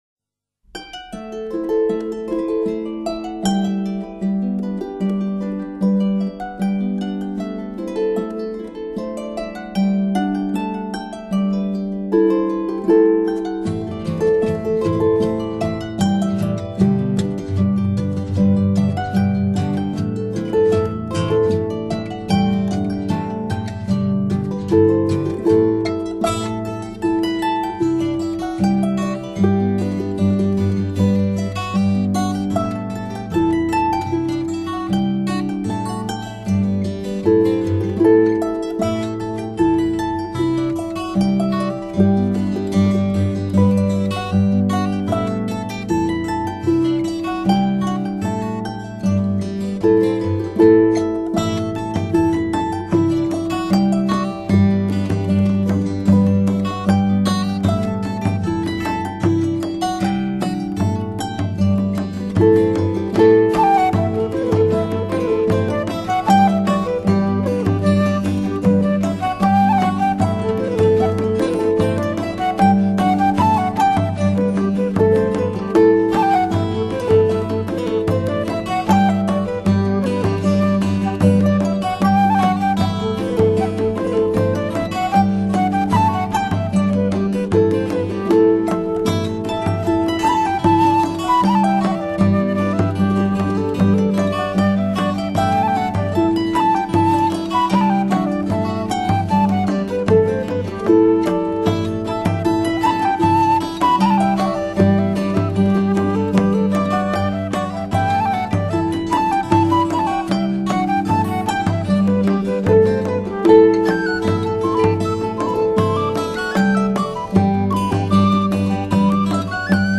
音乐类型：New Age/Gaelic
欢乐的纺轮，劳动的愉悦，爱尔兰居民的笑靥再现